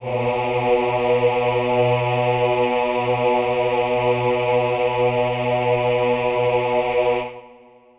Choir Piano (Wav)
B2.wav